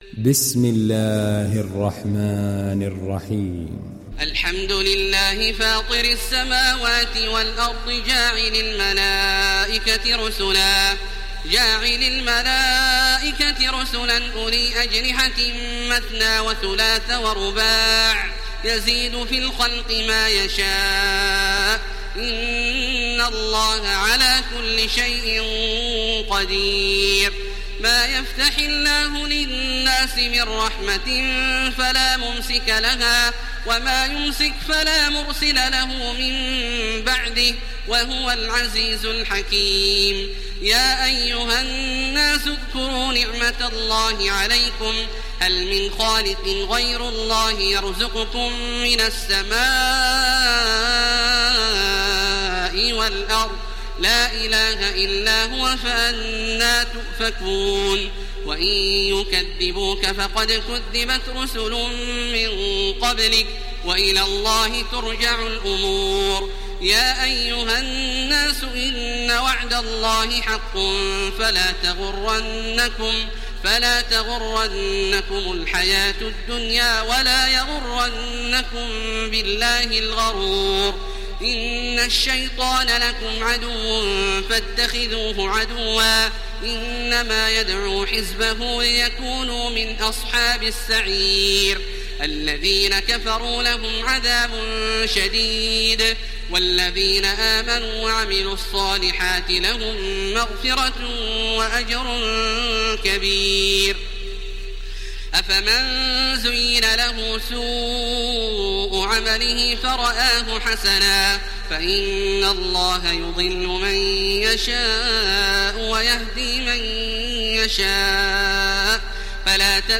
İndir Fatır Suresi Taraweeh Makkah 1430